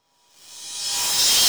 The Fall Reverse Open Hat.wav